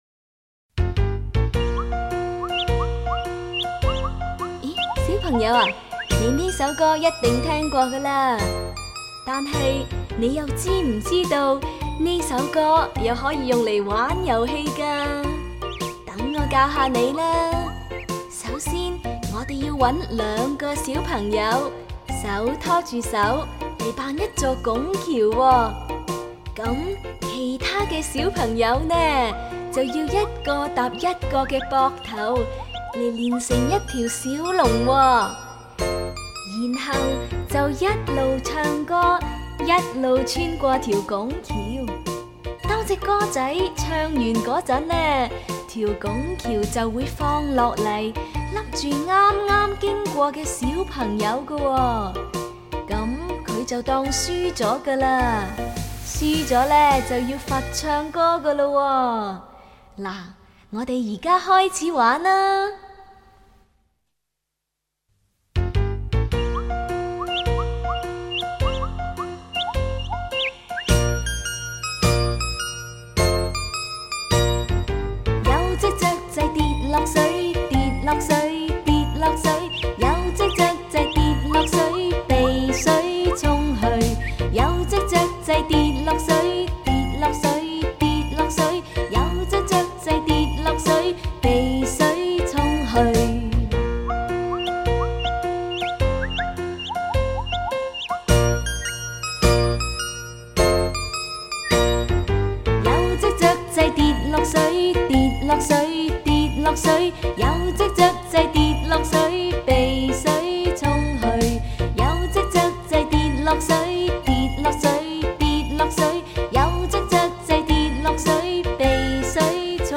一只雀仔跌落水[示唱+伴奏] —— 相关链接 —— .